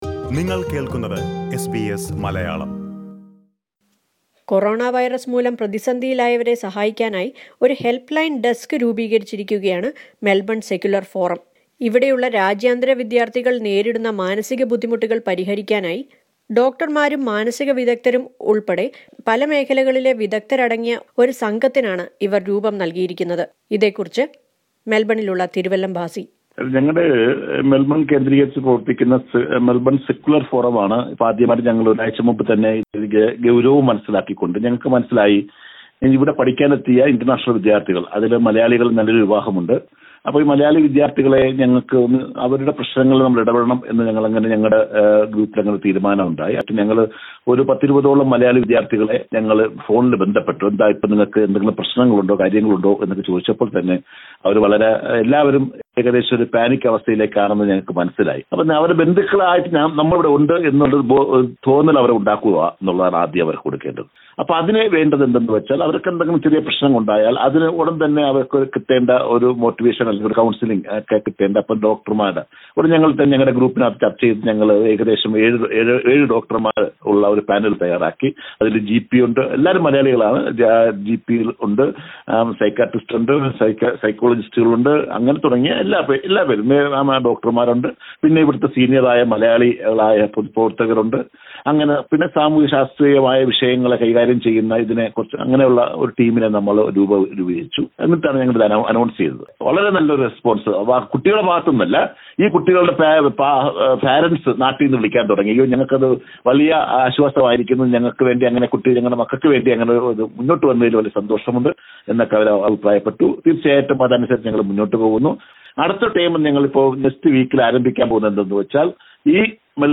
Many Australian Malayalee groups are coming forward to help those in need during coronavirus outbreak. Listen to a report on this.